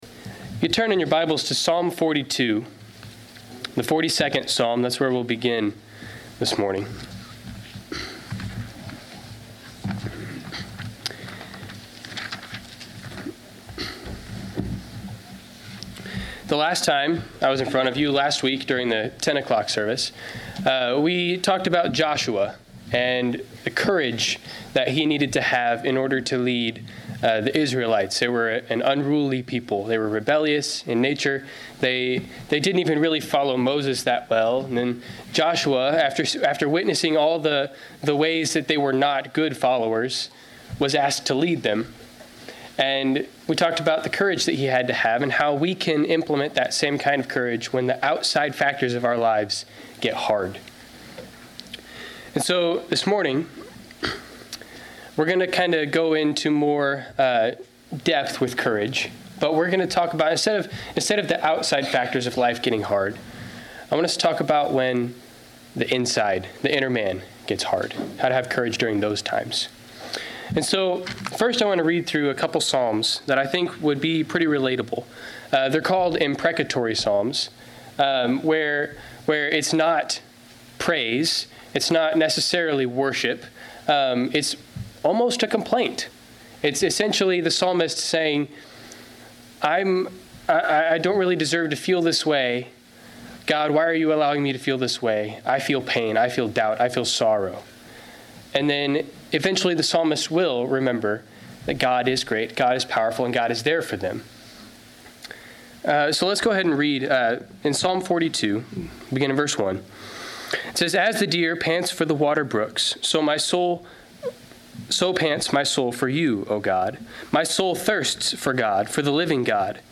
Service Type: Sunday 11:00 AM